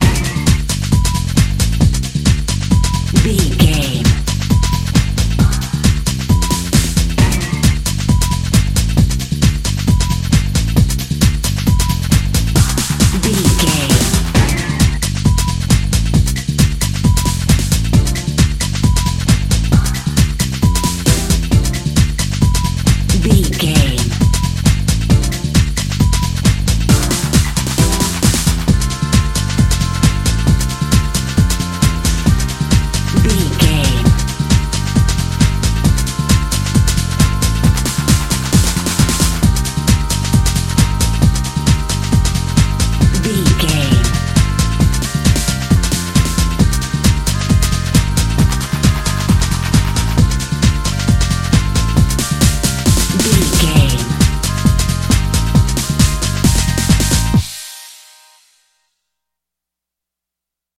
Ionian/Major
D
Fast
synthesiser
drum machine